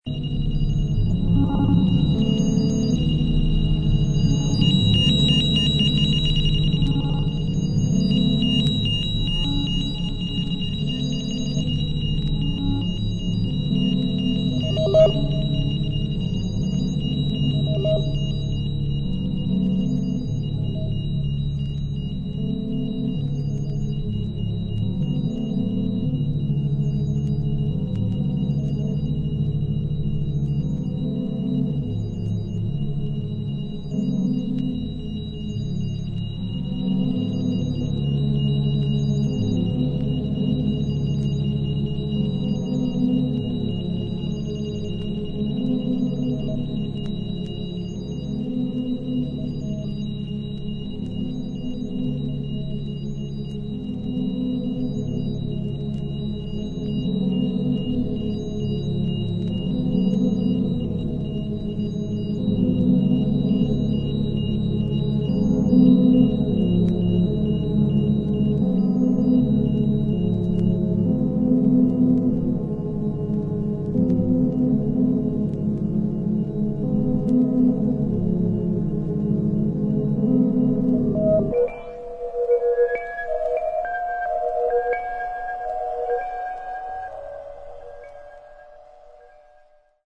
シンプルな編成ながら緻密で、まるで生きもののような深みを感じるエレクトロニクスの音像が堪能できます。